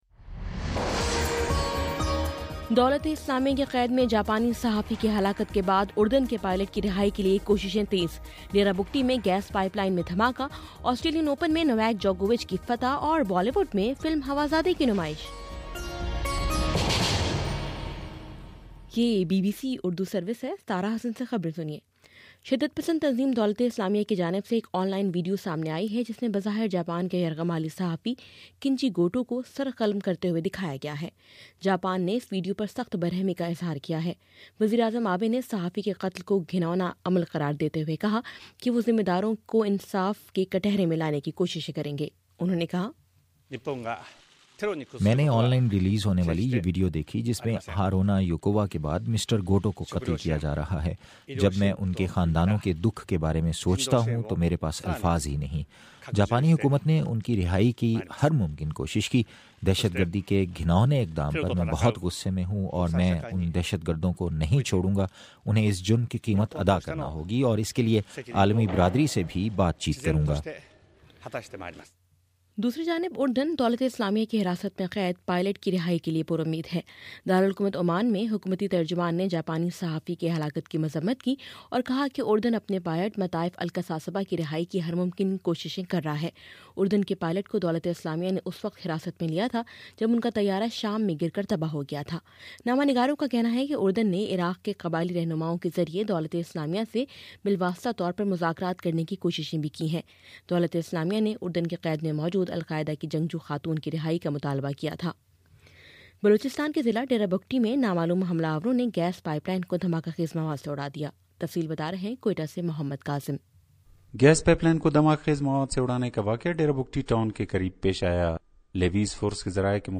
فروری 01: شام چھ بجے کا نیوز بُلیٹن